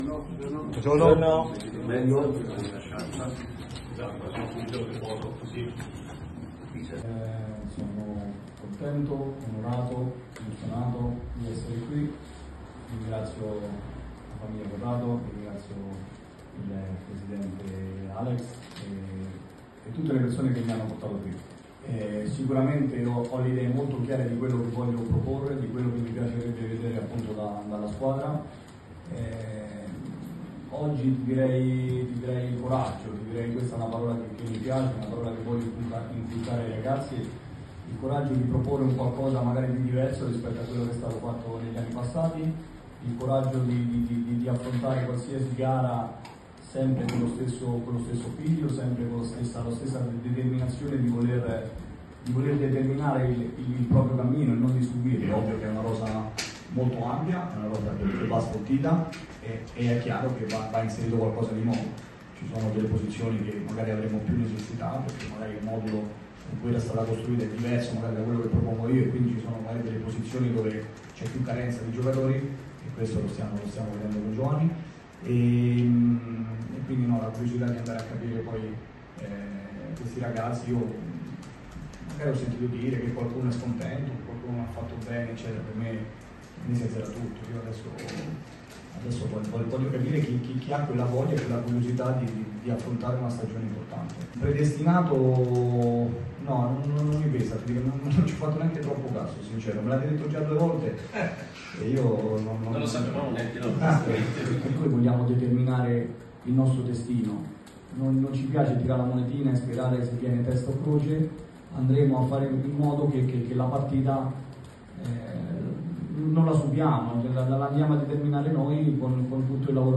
Un estratto direttamente dalla conferenza di presentazione: